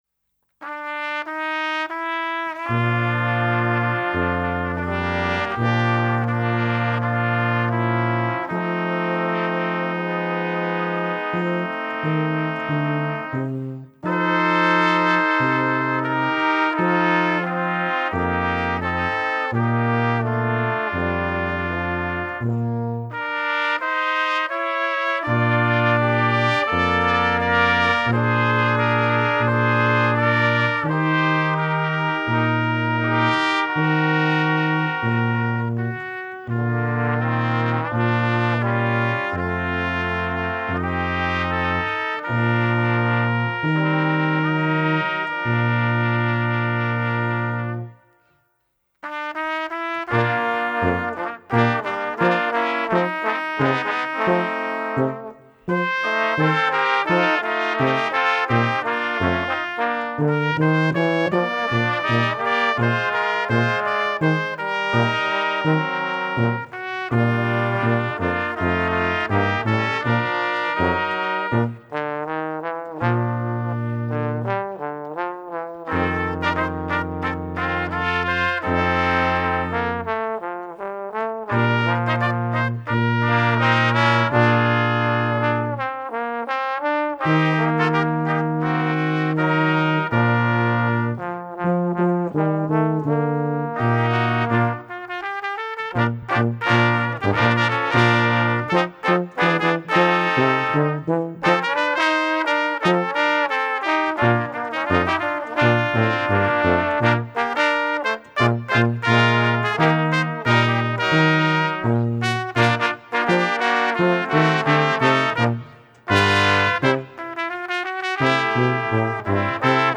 Gattung: 4 Blechbläser
Besetzung: Ensemblemusik für 4 Blechbläser